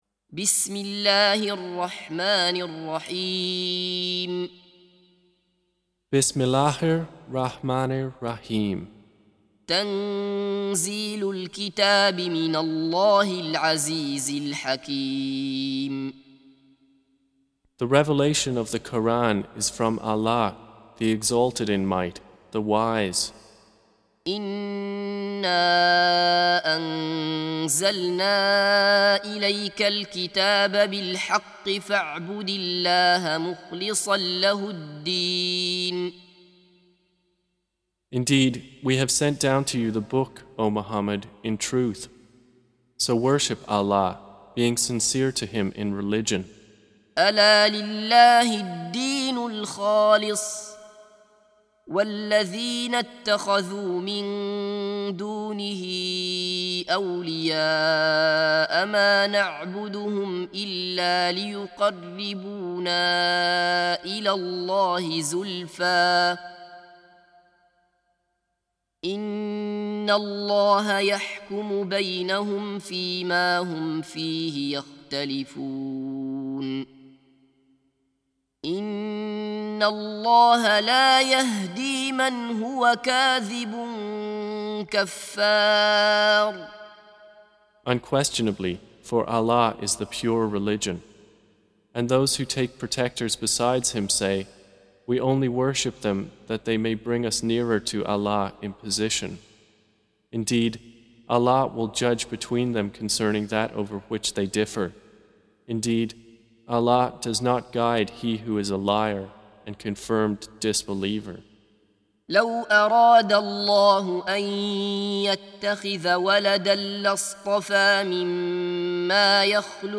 Recitation
Surah Sequence تتابع السورة Download Surah حمّل السورة Reciting Mutarjamah Translation Audio for 39. Surah Az-Zumar سورة الزمر N.B *Surah Includes Al-Basmalah Reciters Sequents تتابع التلاوات Reciters Repeats تكرار التلاوات